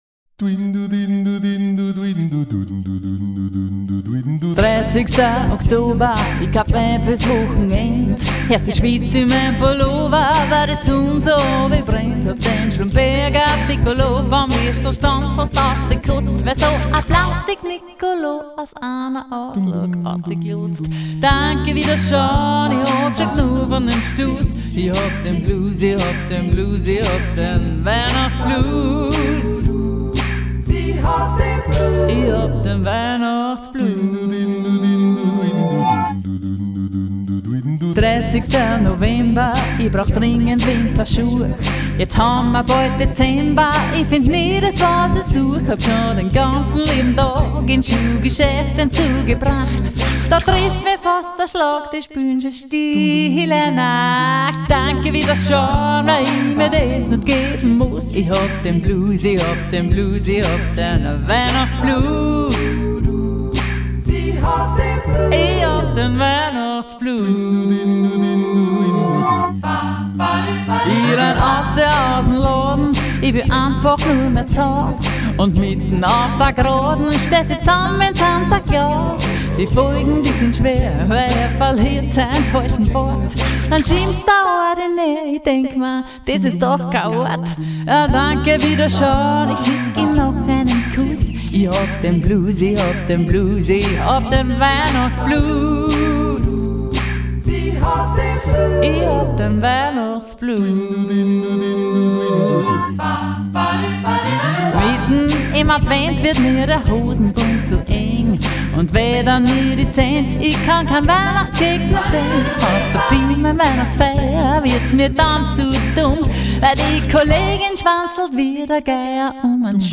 A-cappella-Gruppe